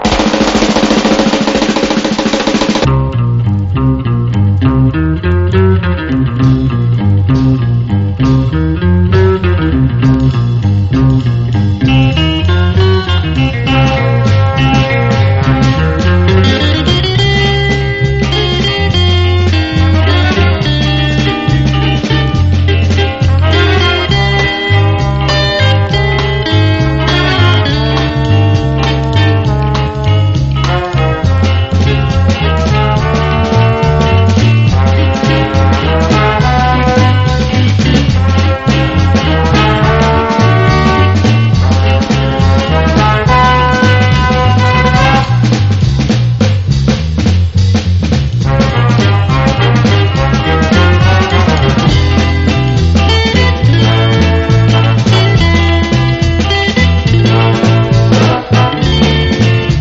爽やかなラテン・ラウンジ！
オルガンとパーカッションが加わっているので、使えるトラック多数です！
暑苦しくない爽やかなラテン・サウンドが素敵。